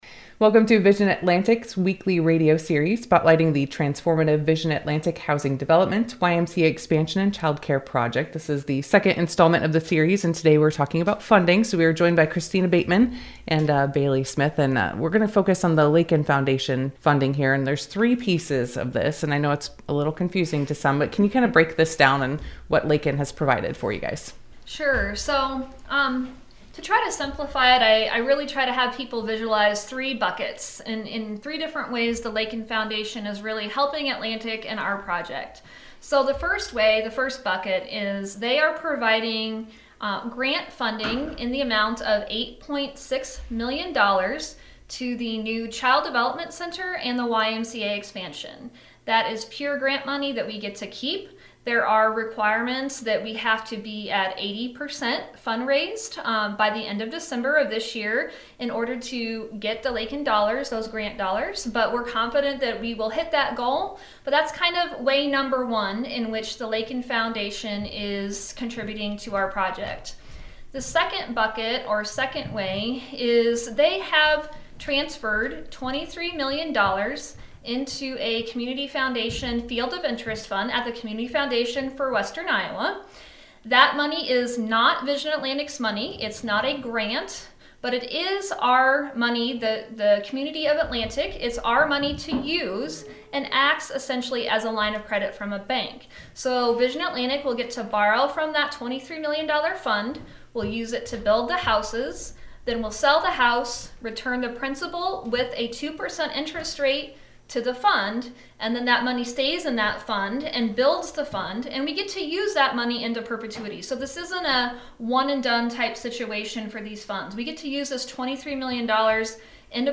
The full interview is posted below.